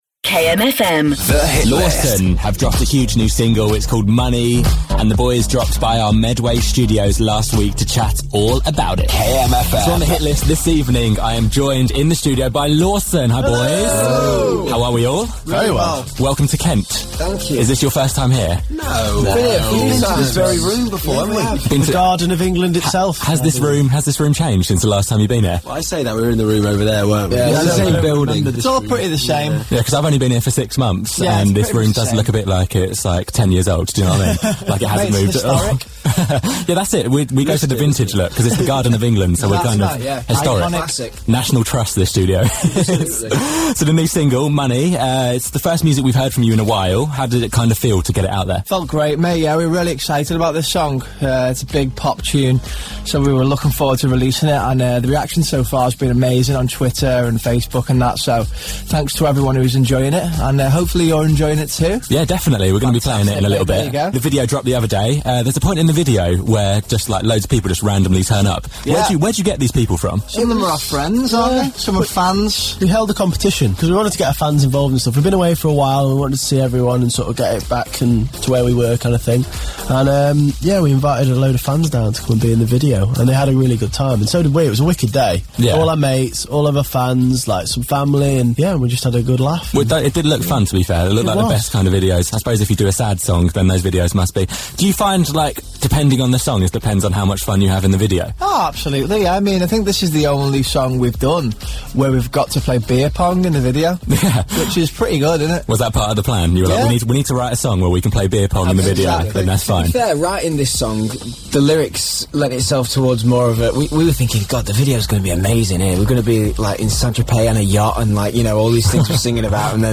Fan Voicemails, dodgy videos and who's who.